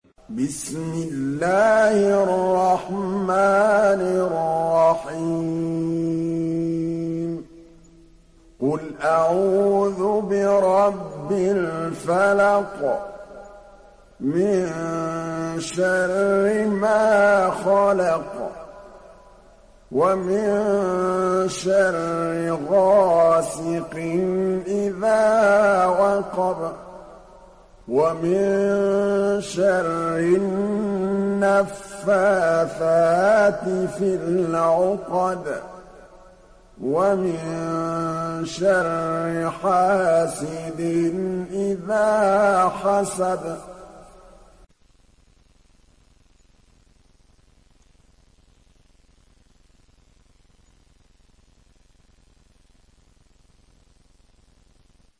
Felak Suresi İndir mp3 Muhammad Mahmood Al Tablawi Riwayat Hafs an Asim, Kurani indirin ve mp3 tam doğrudan bağlantılar dinle